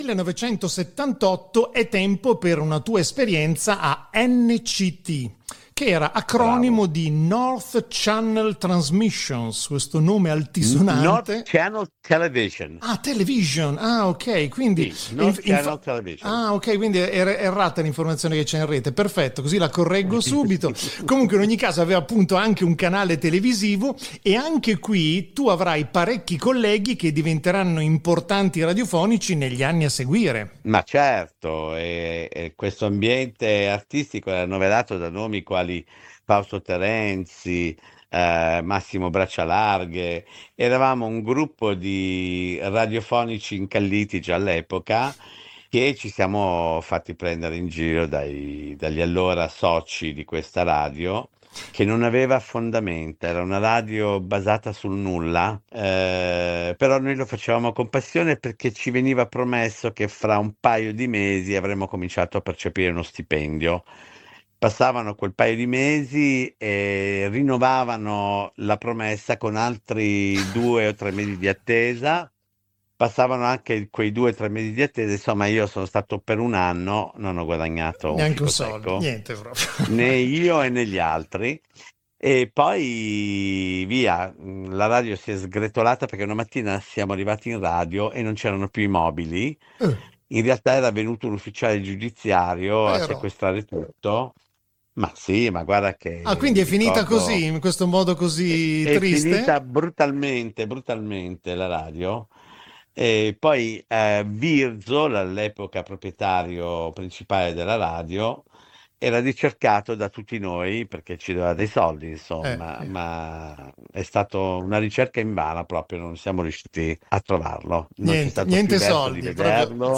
spezzone di intervista rilasciata sul canale youtube "Milano in FM *Seventies-Eighties*"